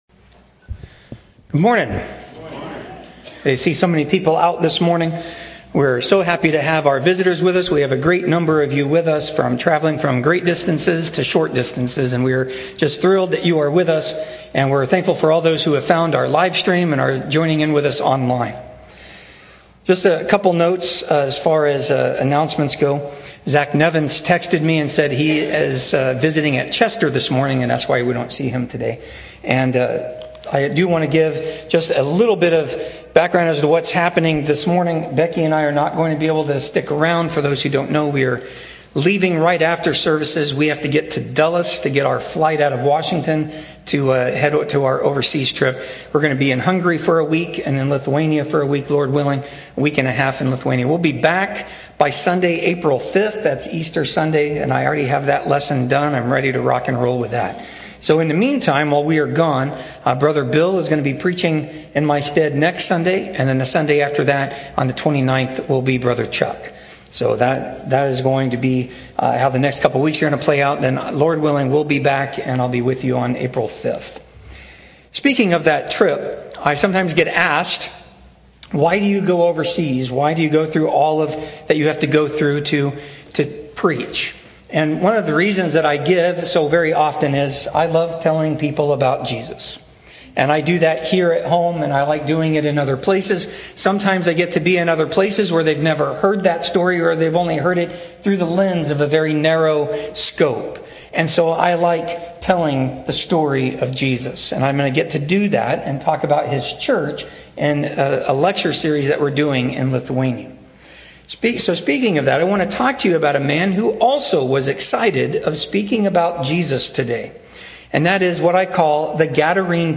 GADARENE_PREACHER_MP3_Mono.mp3